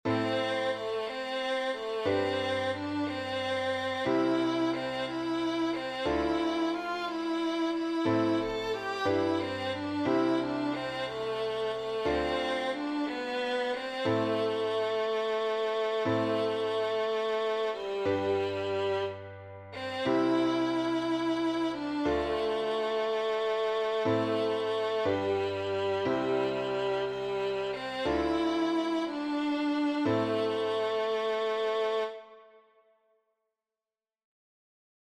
A cappella